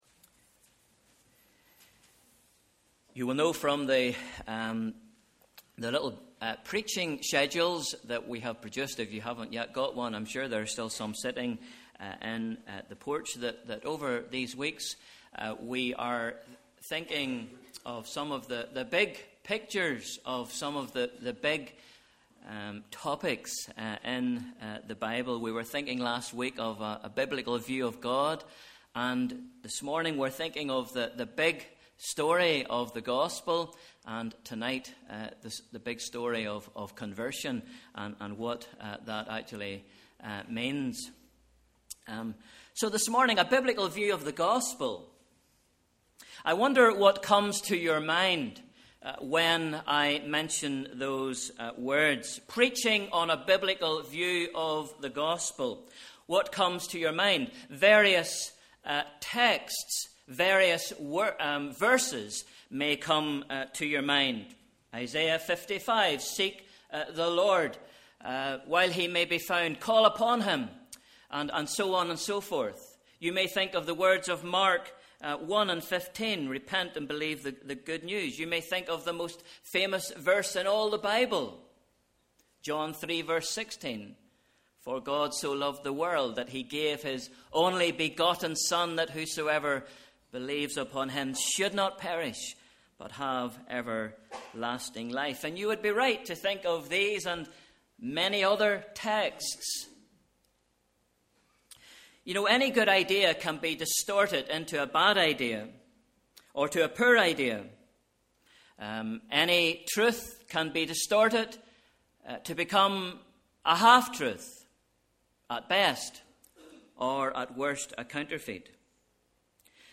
Morning Service: Sunday 1st February 2015 Bible Reading: 1 Corinthians 15 v 1-11